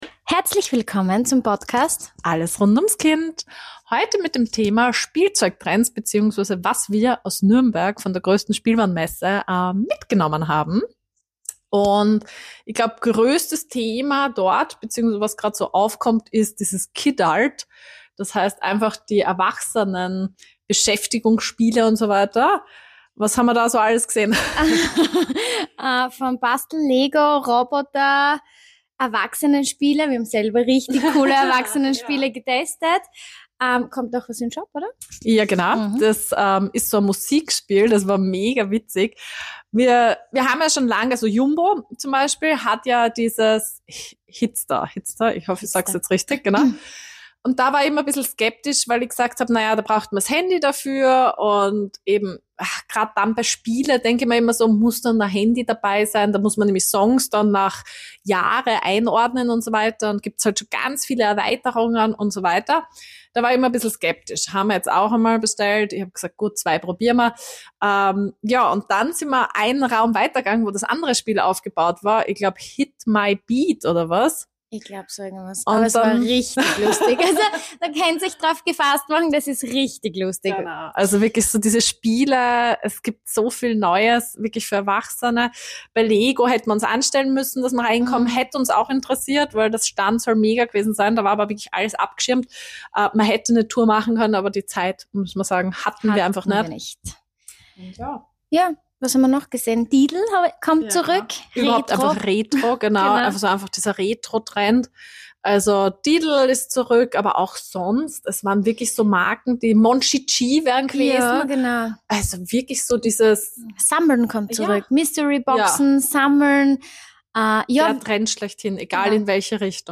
Wir sprechen darüber, warum Erwachsene wieder sammeln, Kinder Überraschungen lieben und Nostalgie plötzlich ganz vorne im Trend liegt. Freut euch auf Einblicke, Highlights und jede Menge Spielspaß direkt von der Spielwarenmesse – für Eltern und alle, die Spielzeug einfach lieben.